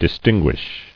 [dis·tin·guish]